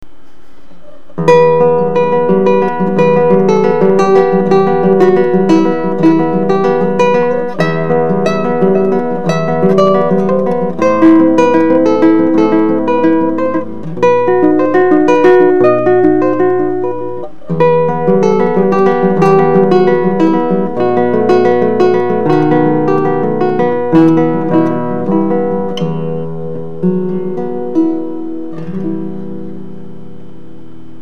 630mm short scale lattice guitar.
Sound Sample of this guitar without the back prior to any adjustments.